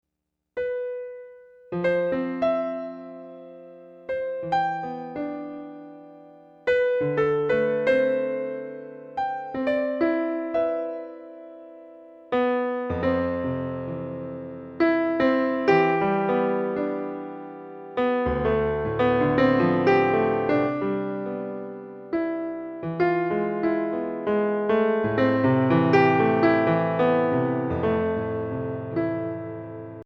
Instrumental Album Download